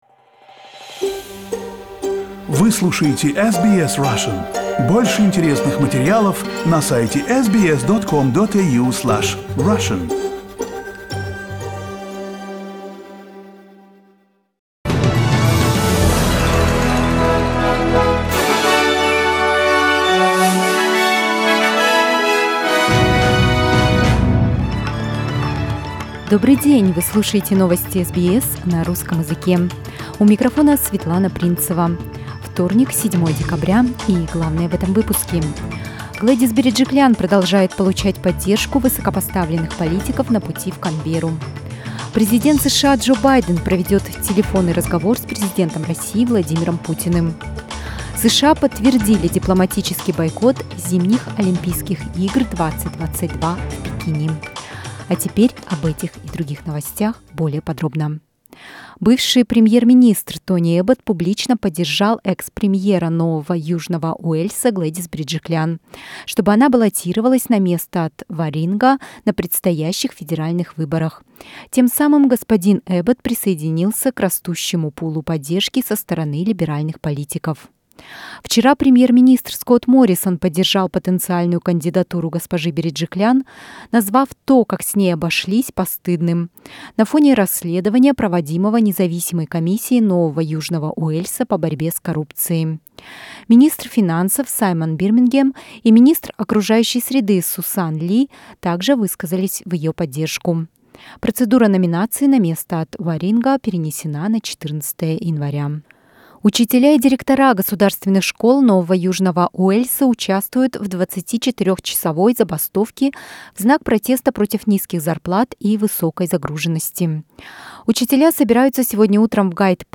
Listen to the latest news headlines from Australia and the world on SBS Russian.